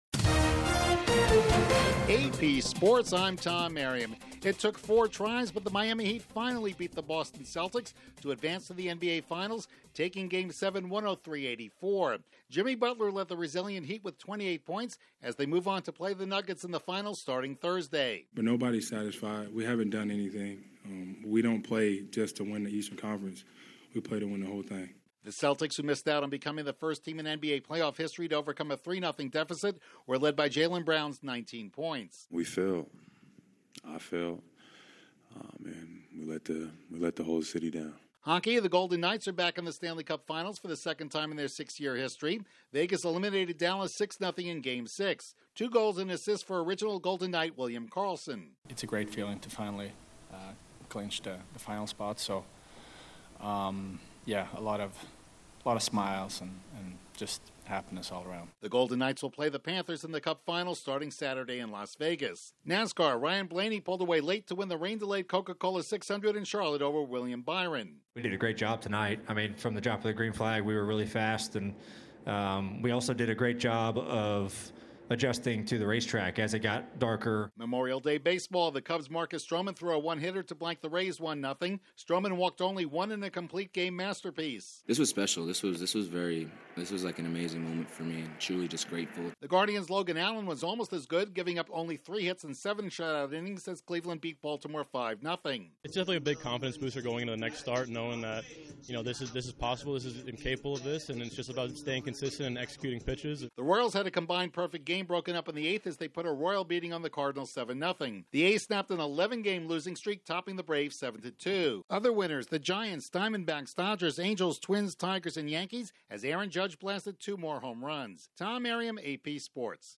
The Heat finally advance to the NBA Finals, the Golden Knights make it to the Stanley Cup Finals, Ryan Blaney is the real thing at the Coca-Cola 600, Marcus Stroman fires a one-hit shutout and Aaron Judge blasts two more homers. Correspondent